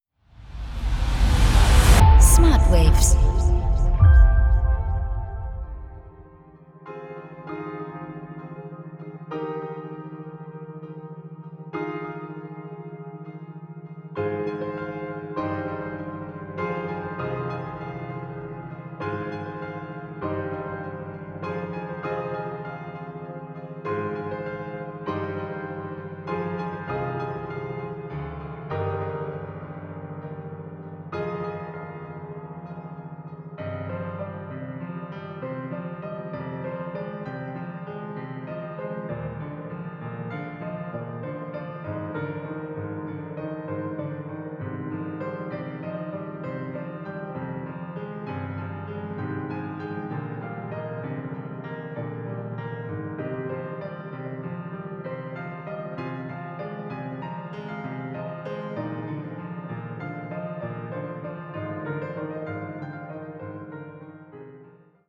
Binaurale & Isochrone Beats